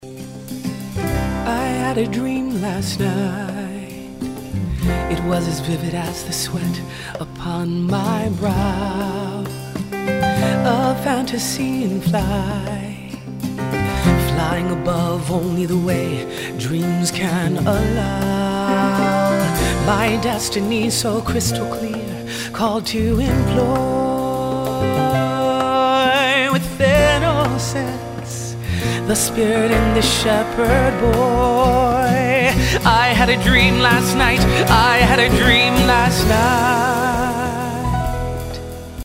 "new musical experience"